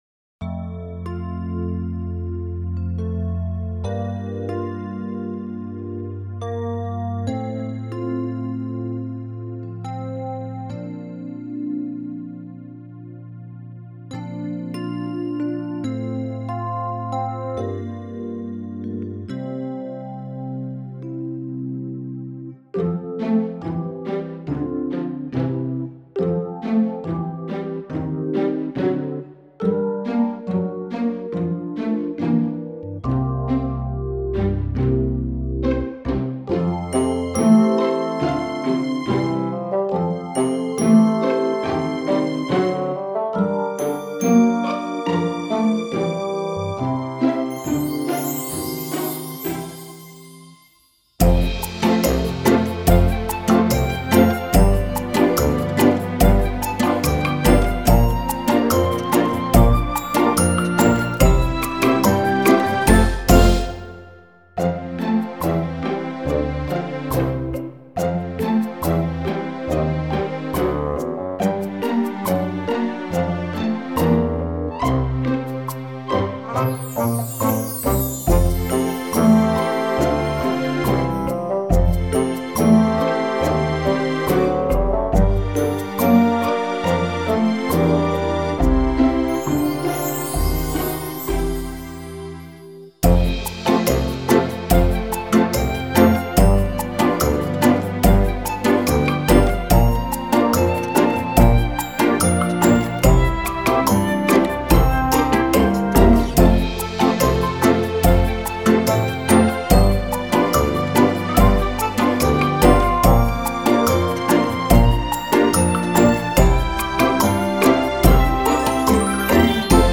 Qui la base musicale (MP3 4.5 MB), per cantarci sopra.